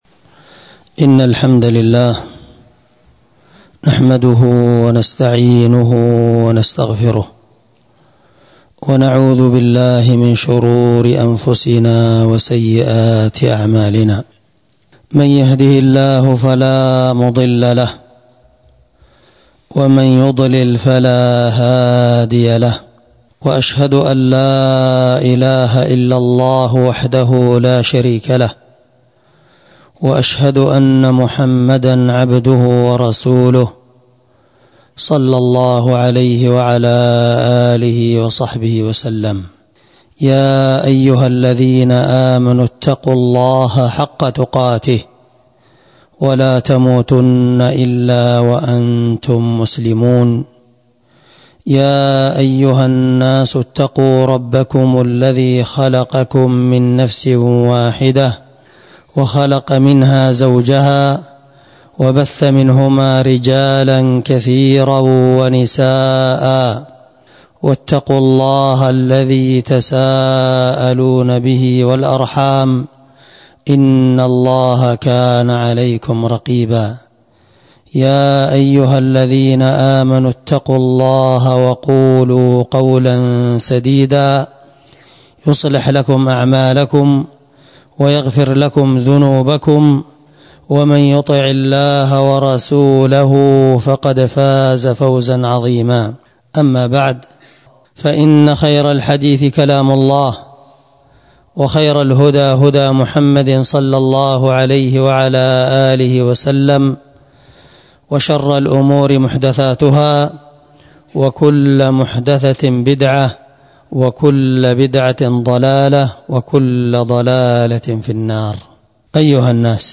خطبة وصف الحال فتنة المال